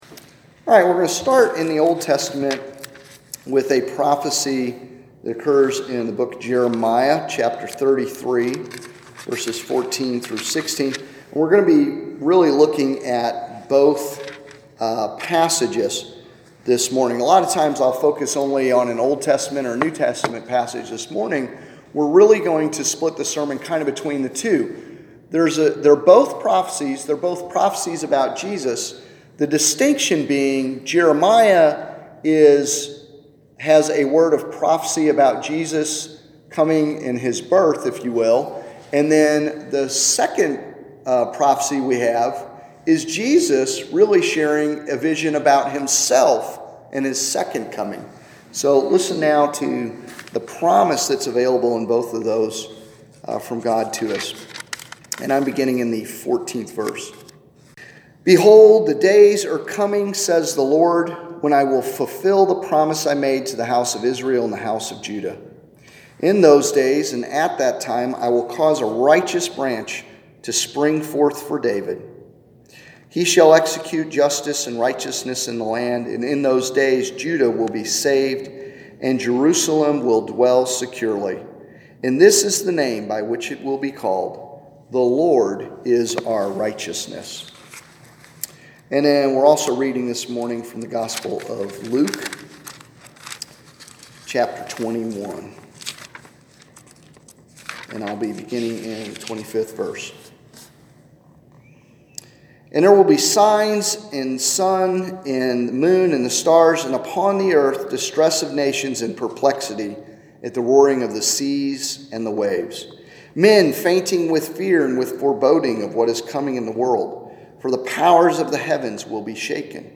Listen to more great sermons on our sermon archive page.